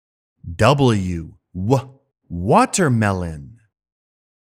音声を聴いて、このゲームの中で使われている単語をフォニックスの読み方で発音してみよう！